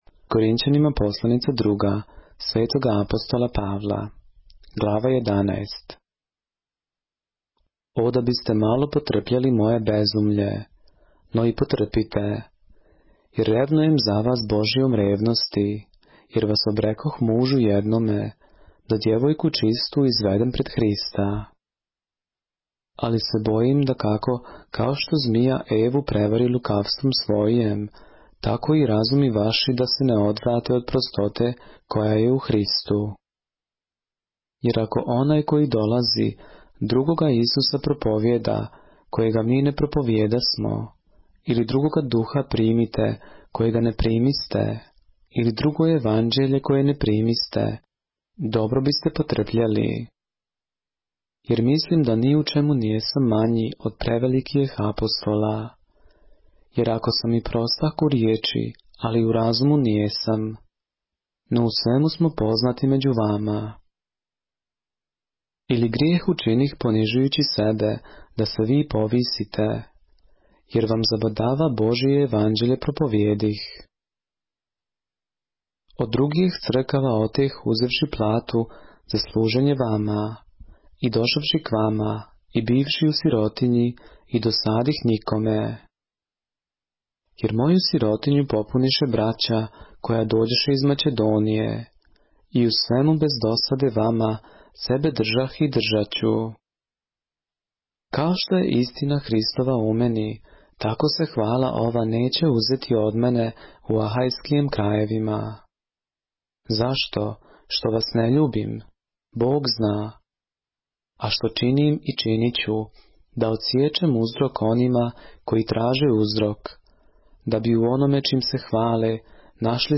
поглавље српске Библије - са аудио нарације - 2 Corinthians, chapter 11 of the Holy Bible in the Serbian language